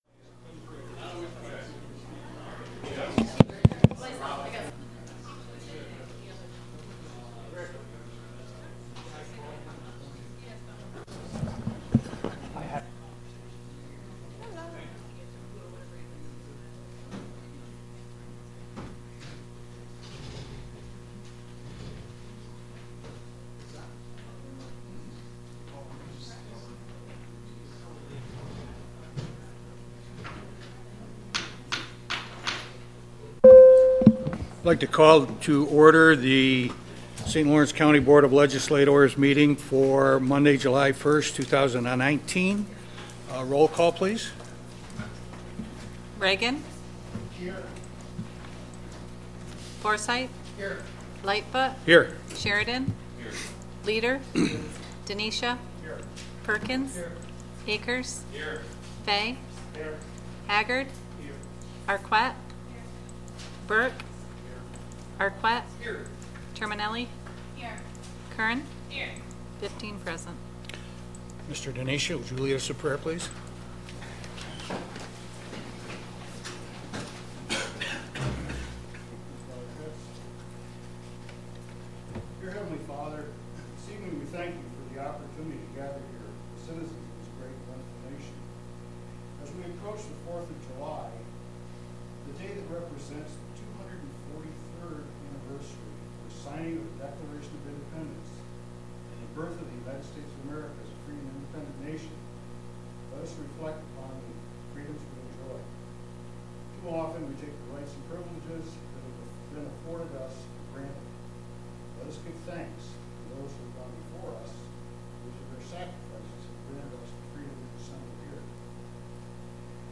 The folder browser user interface will not work properly without it. 07.01.2019 Full Board Recording.mp3 2019 Board of Legislators Meeting Minutes Public 07.01.2019 Full Board Recording.mp3